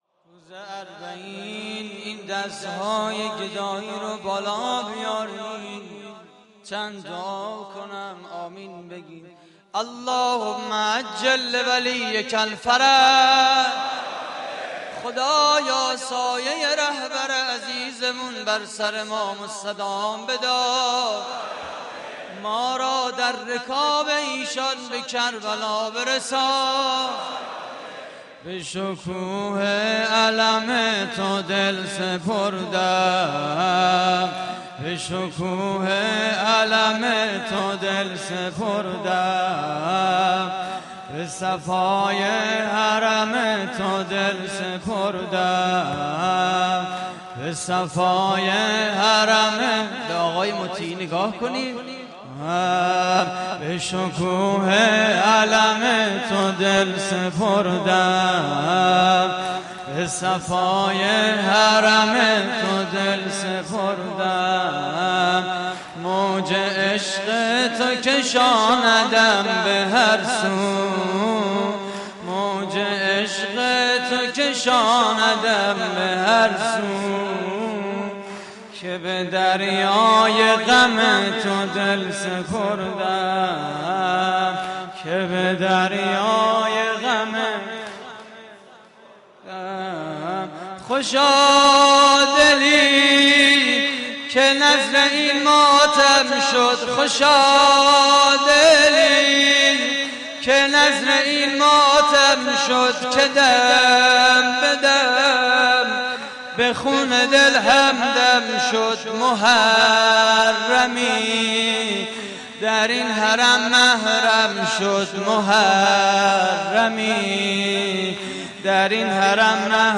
واحد: به شکوه علم تو دل سپردم
مراسم عزاداری اربعین حسینی / حسینیه امام خمینی (ره) – بیت رهبری